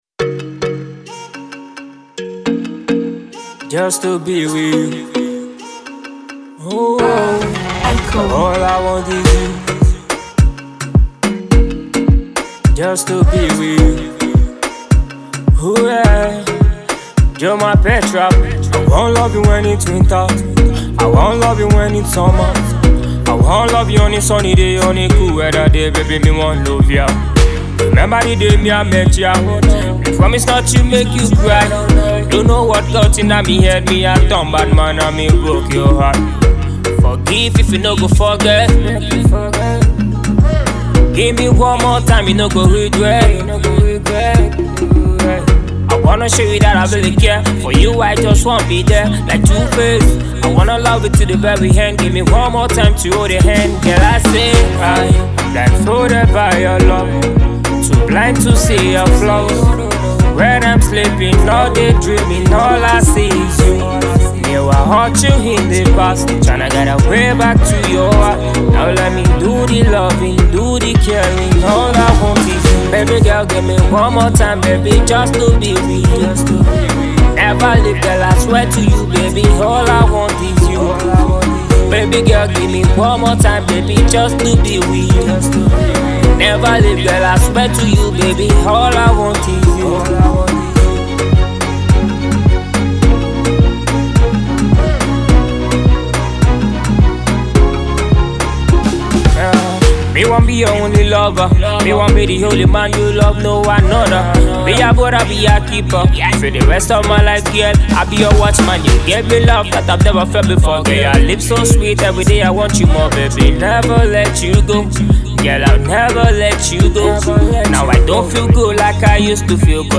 Nigerian reggae-dancehall singer